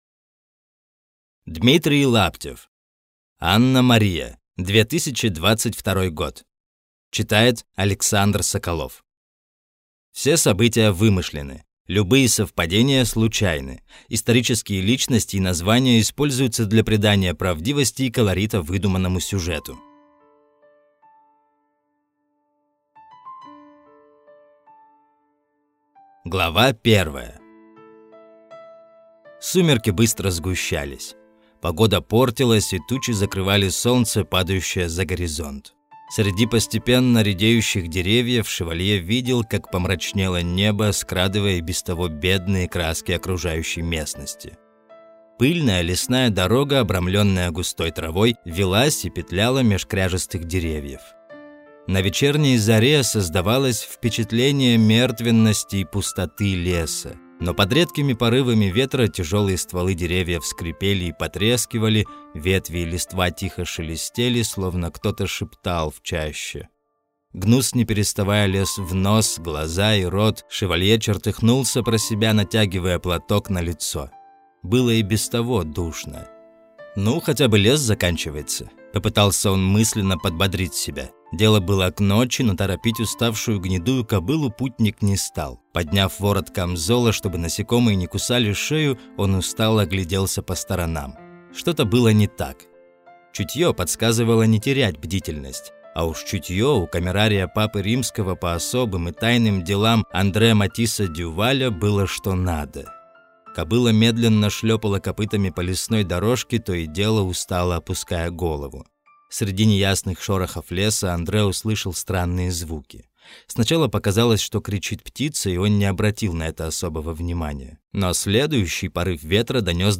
Аудиокнига Анна-Мария | Библиотека аудиокниг